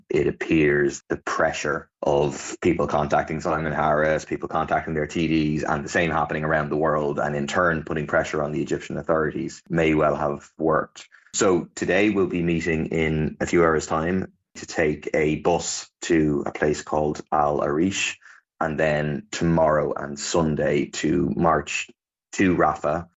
Deputy Murphy says the delegation has been successful so far, after arriving safely last night: